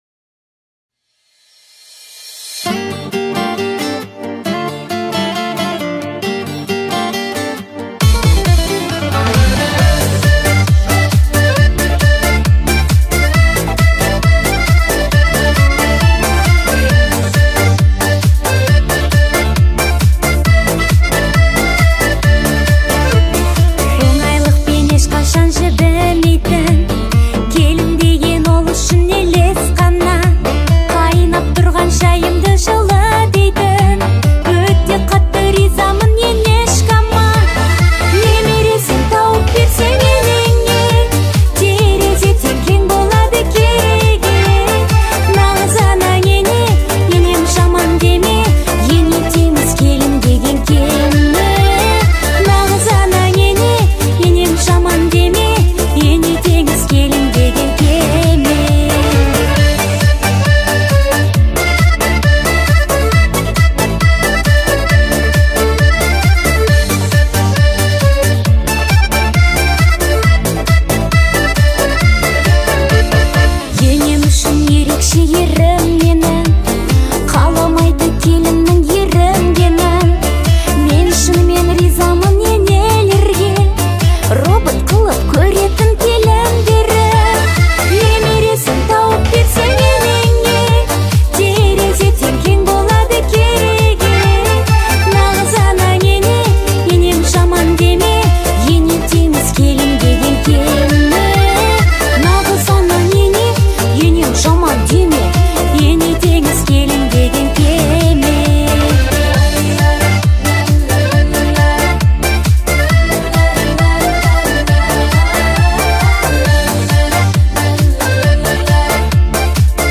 что подчеркивает её мощный вокал.